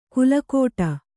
♪ kulukōṭa